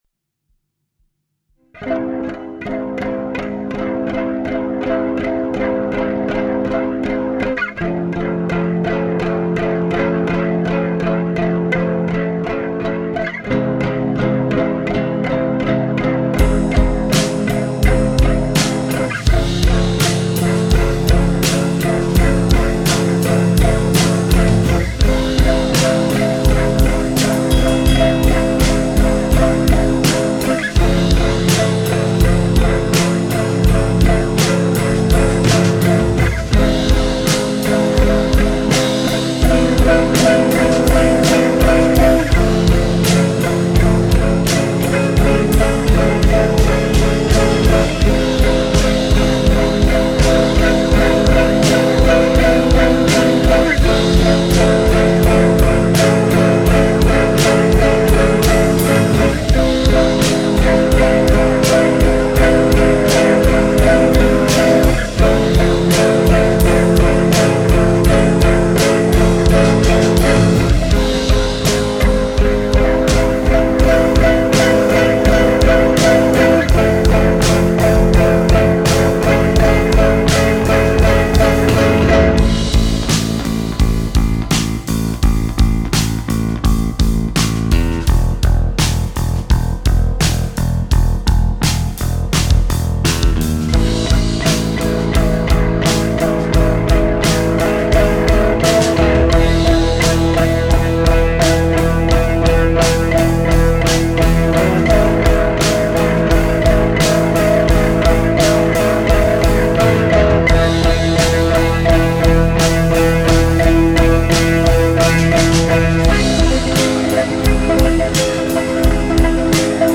Elliott Smith Style, Slow Strum Distorted Version